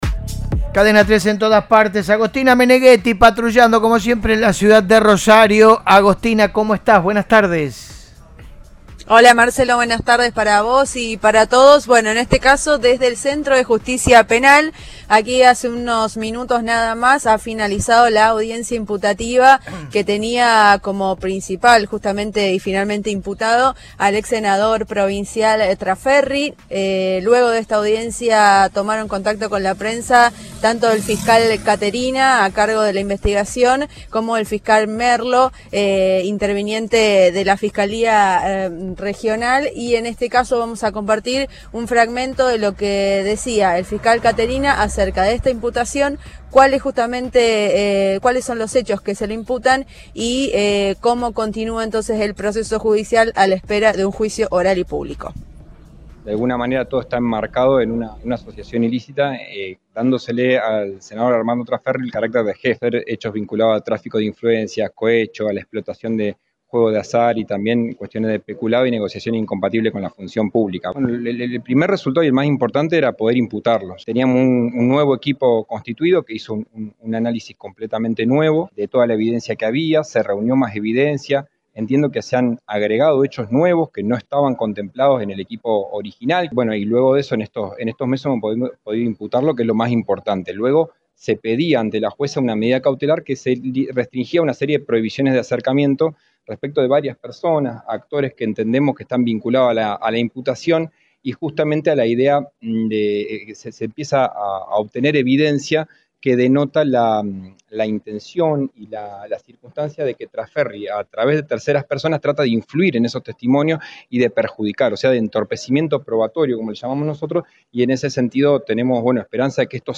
Audio. Imputaron al senador Traferri en Rosario: hablaron los fiscales.